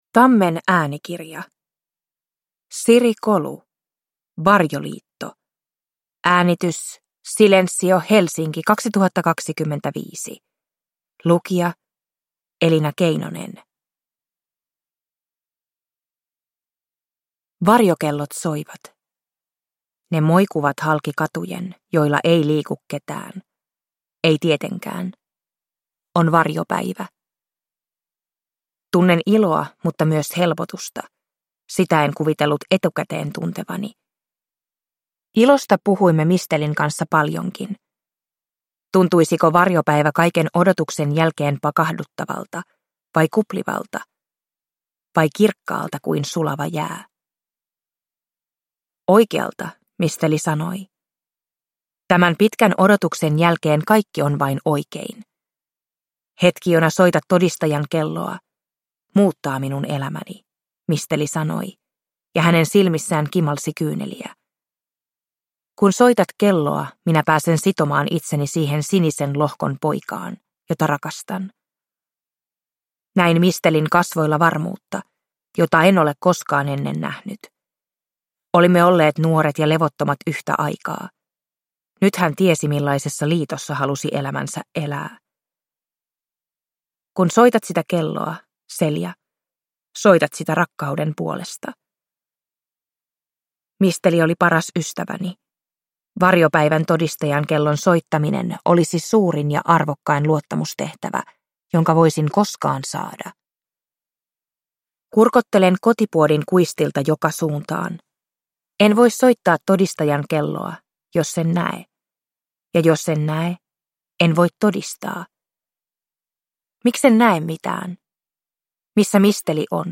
Varjoliitto (ljudbok) av Siri Kolu